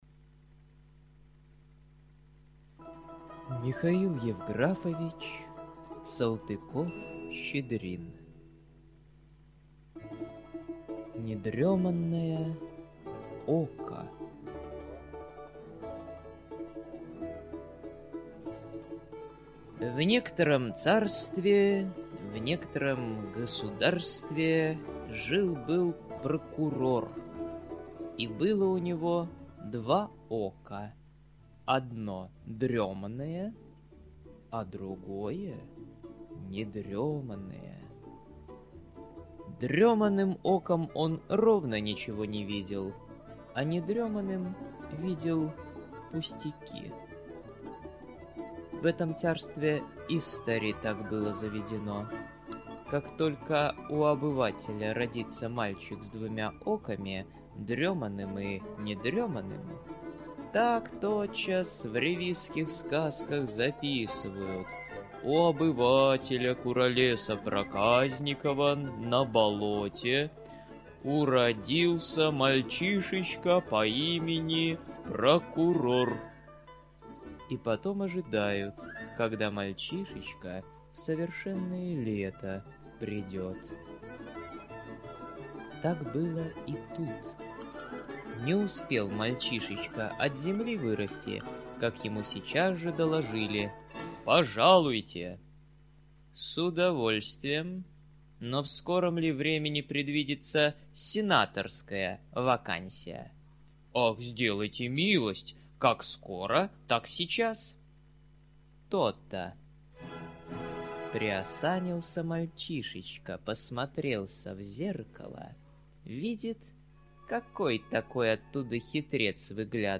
Недреманное око - аудиосказка Михаила Салтыкова-Щедрина- слушать онлайн